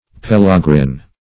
Search Result for " pellagrin" : The Collaborative International Dictionary of English v.0.48: Pellagrin \Pel"la*grin\ (p[e^]l"l[.a]*gr[i^]n), n. One who is afficted with pellagra.
pellagrin.mp3